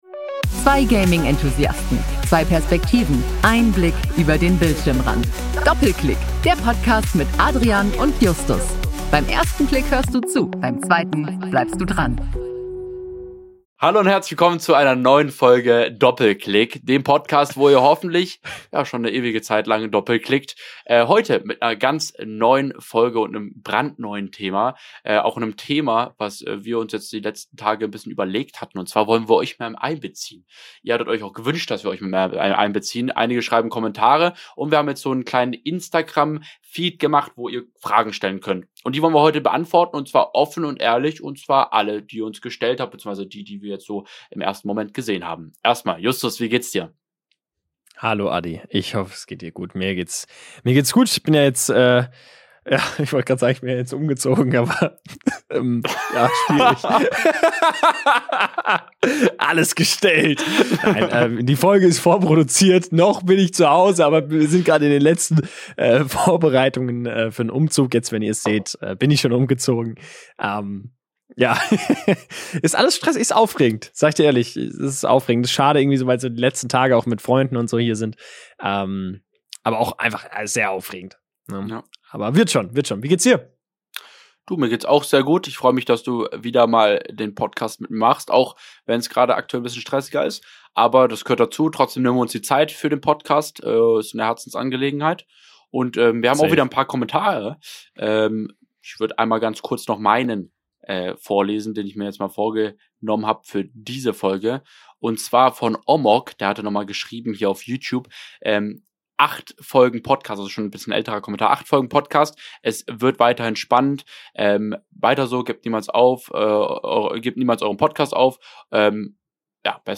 Eine ehrliche und tiefgründige Diskussion über das Leben in der digitalen Welt.